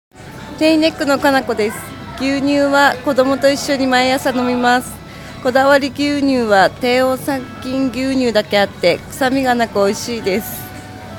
試飲いただいたお客様の生の声
リンクをクリックするとこだわり牛乳を試飲いただいた皆様からの感想を聞くことができます。
4月8日（火）15:00～18:00　ビッグハウス 新川店
お客様の声8